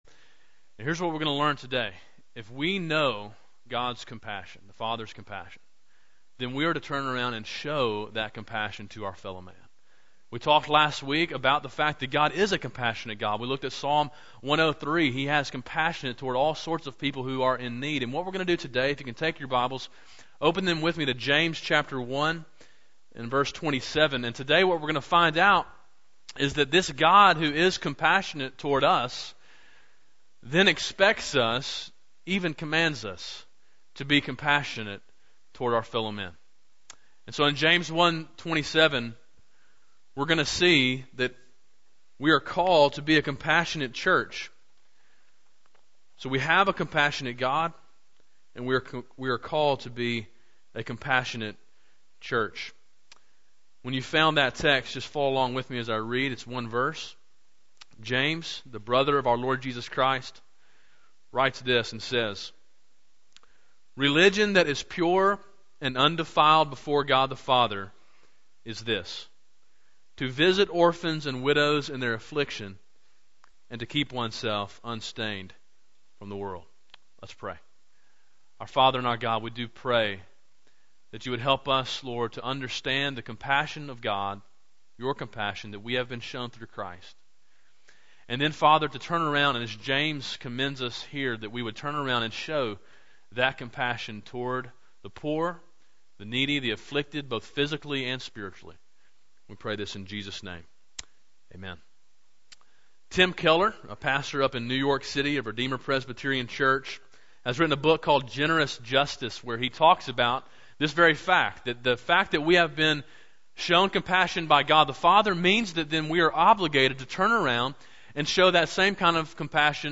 Sermon Audio: James 1:27, “A Compassionate Church” – Calvary Baptist Church